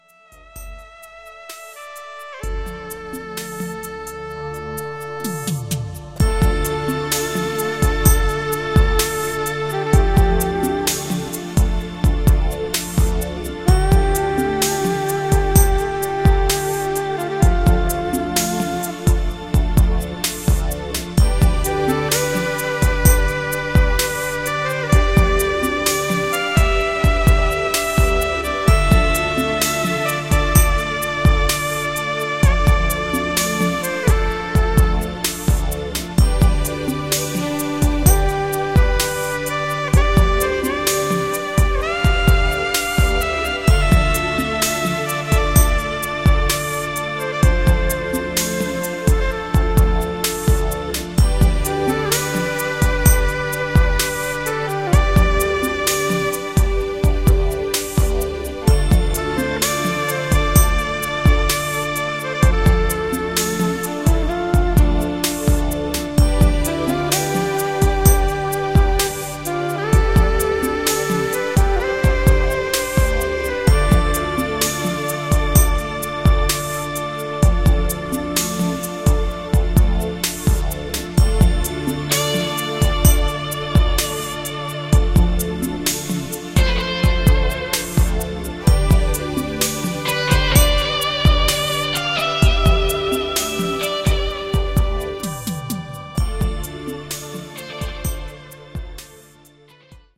Introduction: Space Night [ RealAudio ] [ MP3 ] Ambient Theme: Away From Her [ RealAudio ] [ MP3 ] Roland and Korg synthesisers were used for this project.